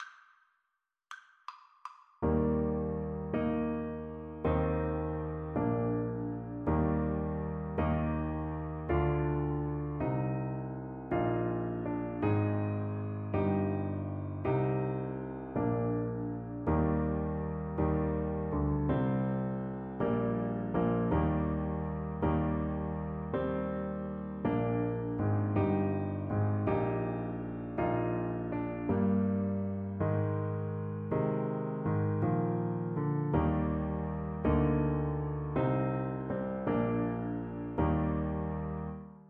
Play (or use space bar on your keyboard) Pause Music Playalong - Piano Accompaniment Playalong Band Accompaniment not yet available transpose reset tempo print settings full screen
Trumpet
3/4 (View more 3/4 Music)
Gentle one in a bar (. = c. 54)
Bb4-Eb6
Eb major (Sounding Pitch) F major (Trumpet in Bb) (View more Eb major Music for Trumpet )